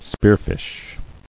[spear·fish]